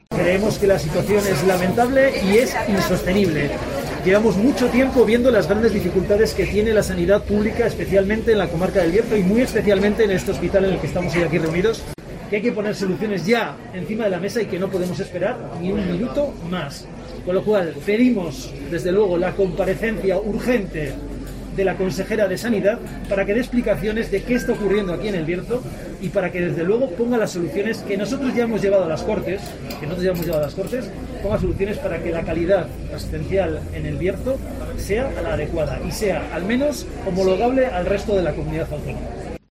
Escucha aquí a Diego Moreno, coordinador de Sanidad en el Grupo Parlamentario Socialista de las Cortes regionales